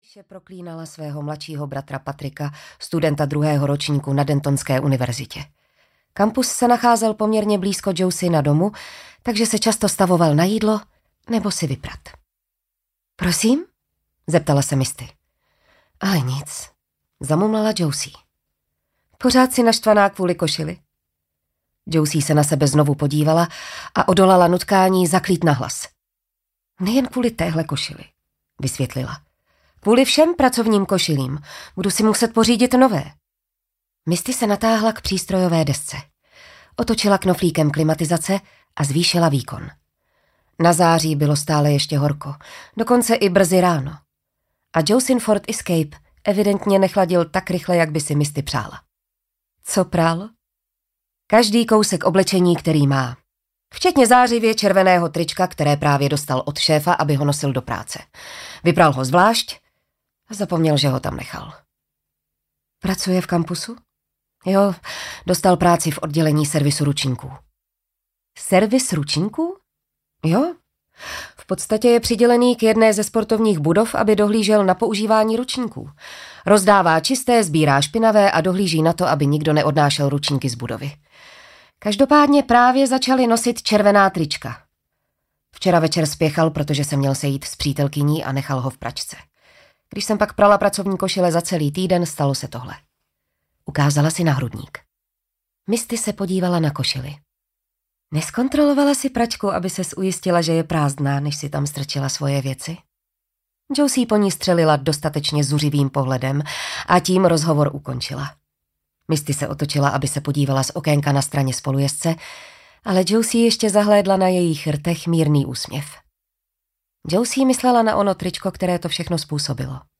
Smrtící nádech audiokniha
Ukázka z knihy